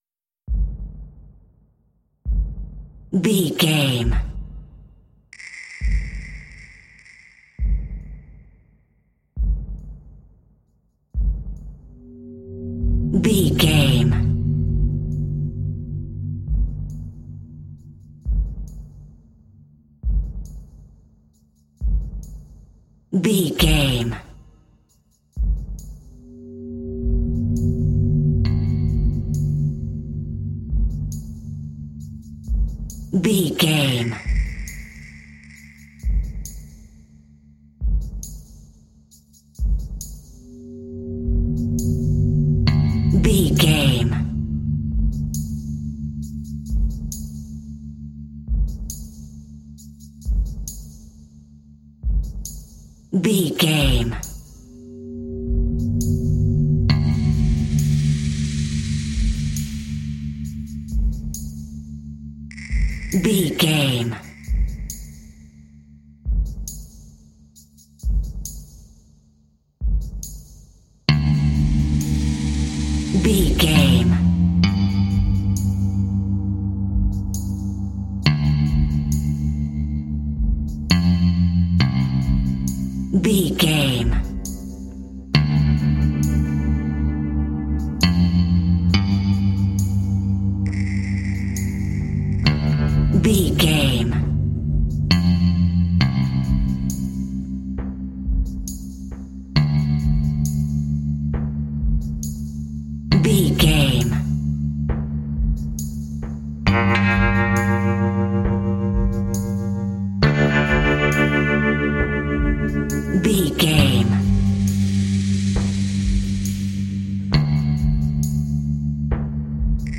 Aeolian/Minor
Slow
fiddle
ominous
electric guitar
tremolo